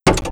IDG-A32X/Sounds/Cockpit/relay-apu.wav at c441ce4683bdb3864a9194f021672ea926b1ee4a
relay-apu.wav